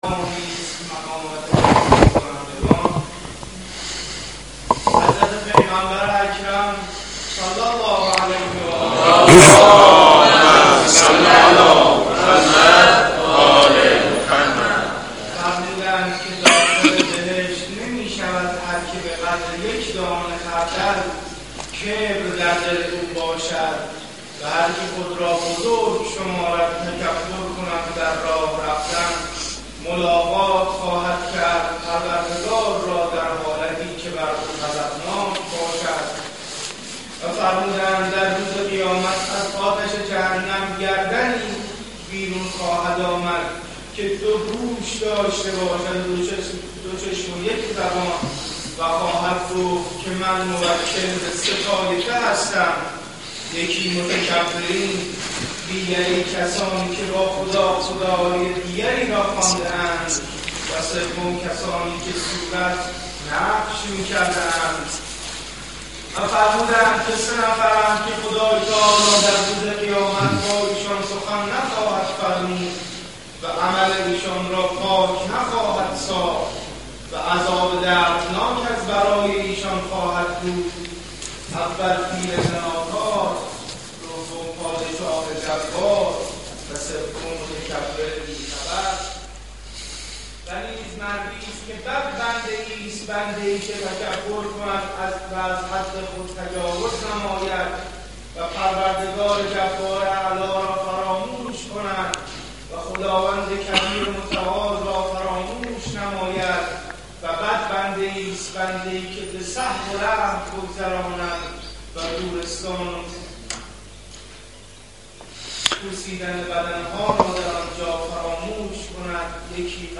سخنرانی های مرحوم آیت الله مجتهدی تهرانی بخش چهارم